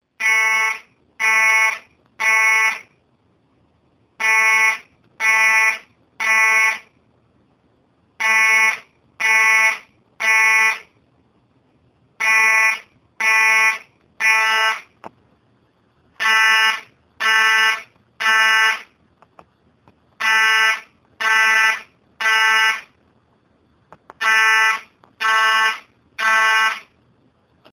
firealarm.ogg